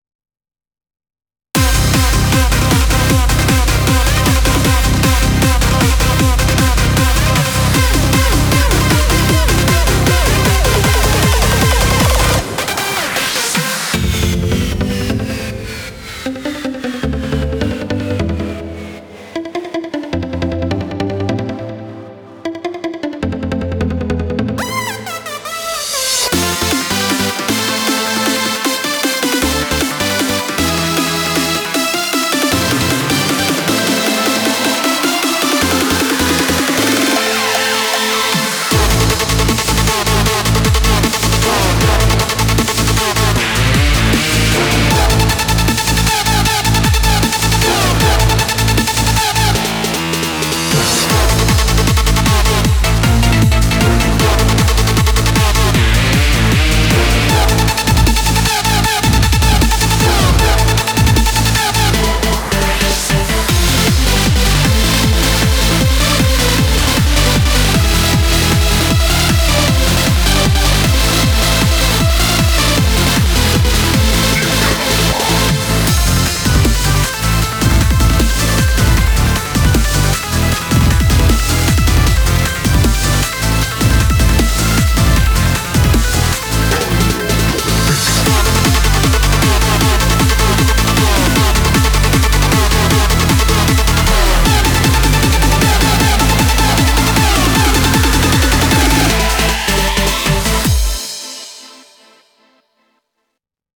BPM75-155
Audio QualityMusic Cut